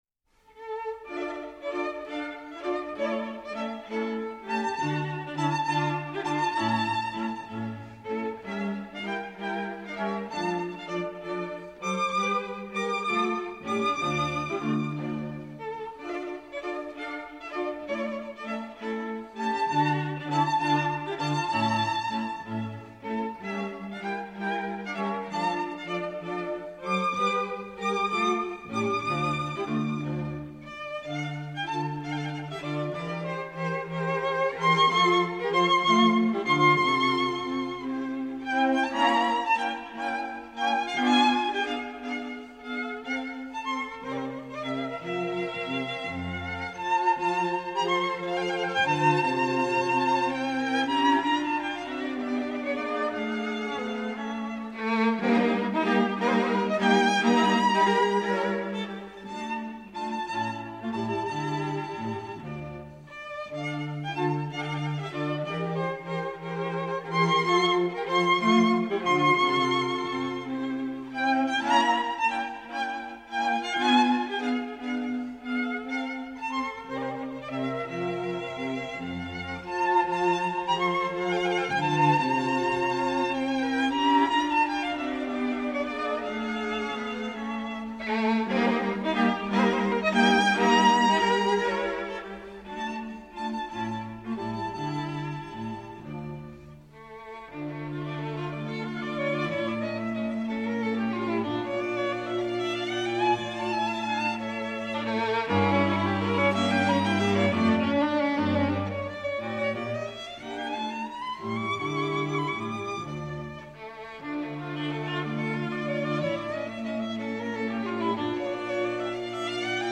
String Quartet in D minor
Allegretto ma non troppo